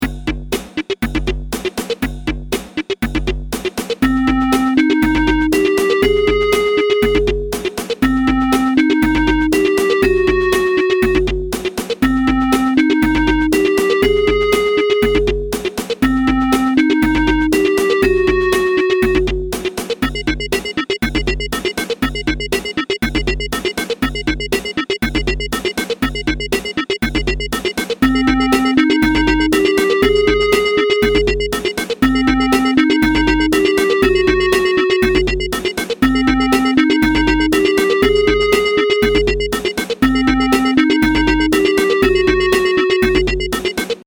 Bucle de Electro
Música electrónica
melodía
repetitivo
sintetizador